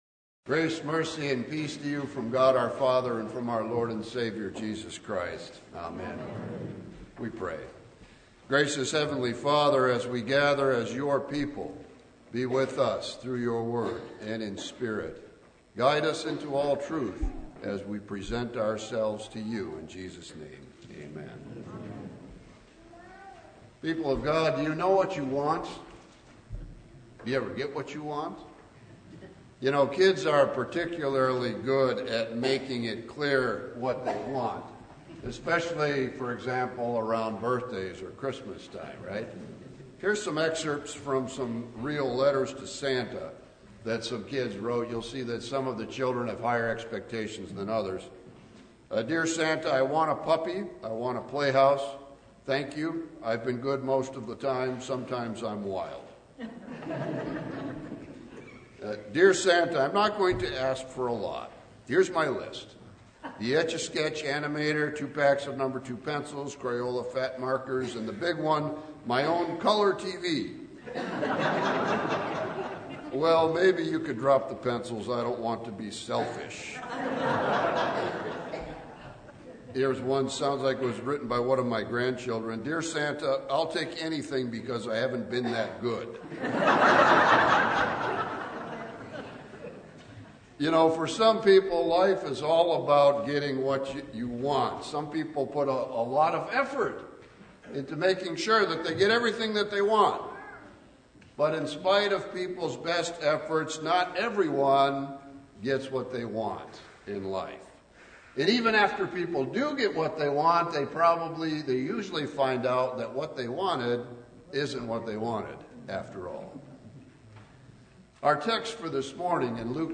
Preacher: Visiting Pastor Passage: Luke 2:22-32 Service Type: Sunday